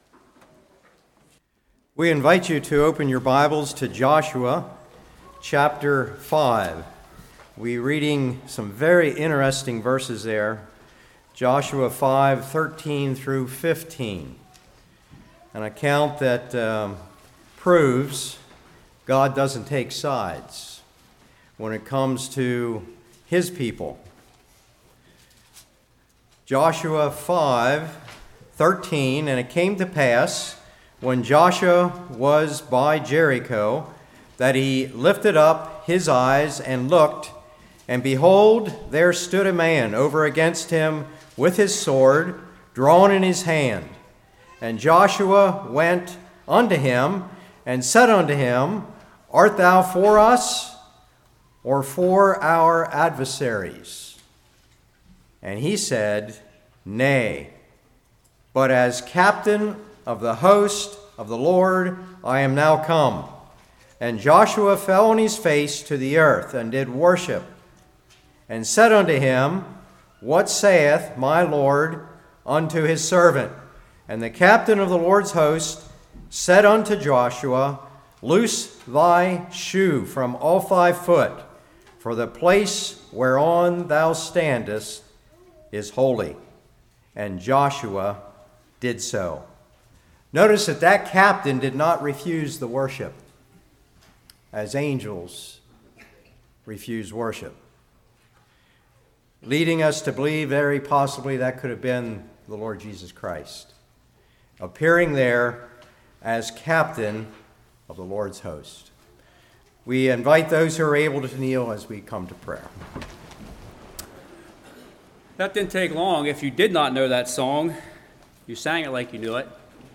Joshua 5:13-15 Service Type: Revival What Should I Do?